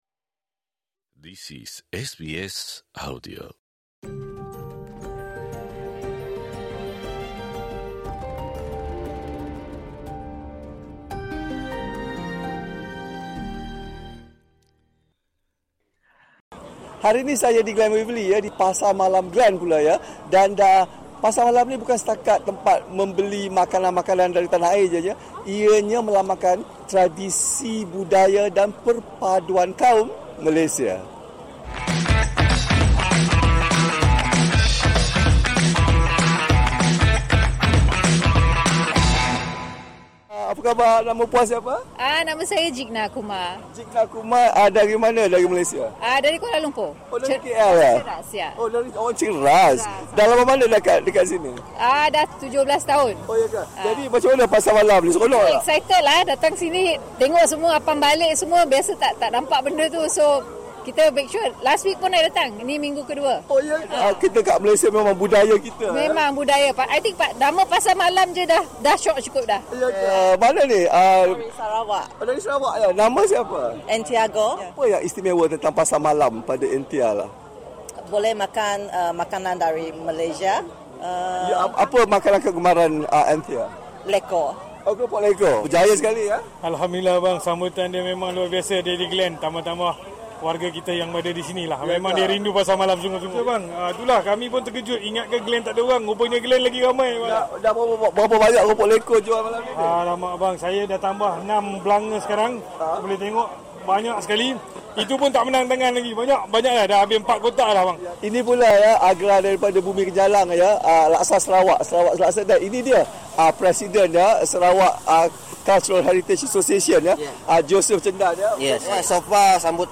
Warga Malaysia berbilang kaum memeriahkan Pasar Malam Glen Waverley di Victoria.